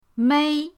mei1.mp3